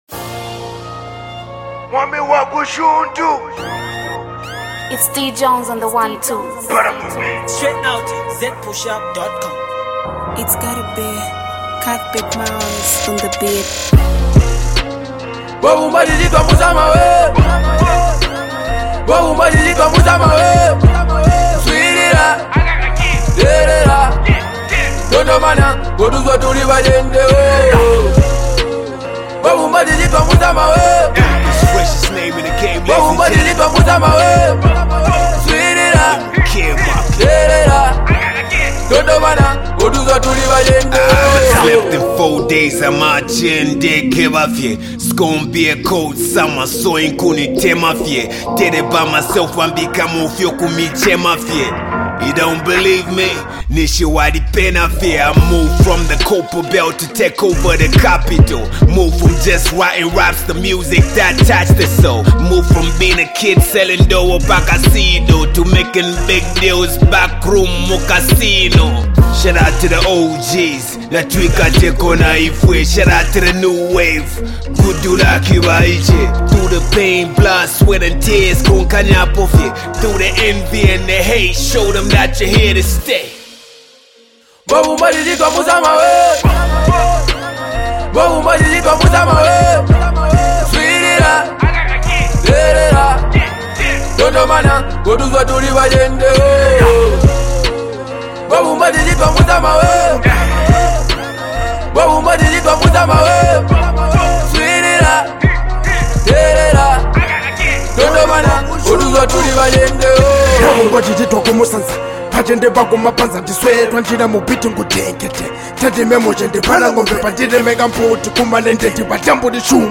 in it they all spit dope bars